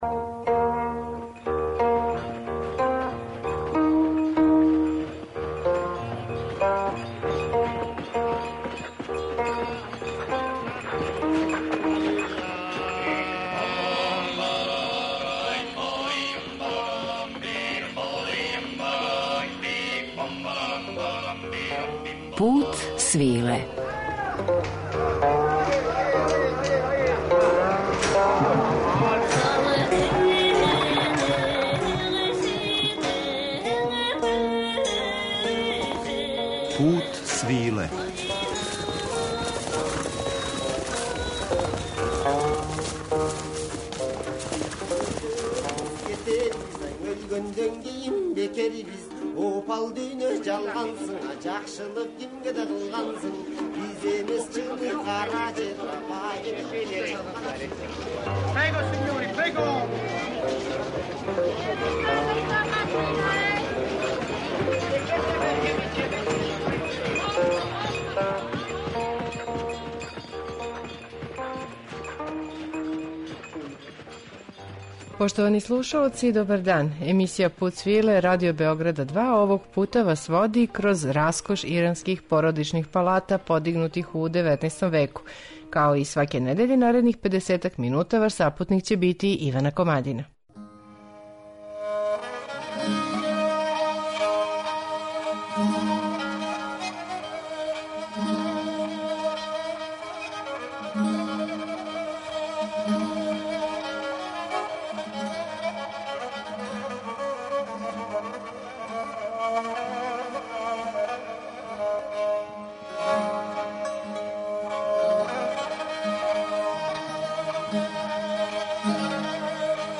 У данашњем Путу свил е, завирићемо у раскош ових персијских палата у музичком друштву великог иранског уметника Кајхана Калхора, виртуоза на традиционалном инструменту каманче.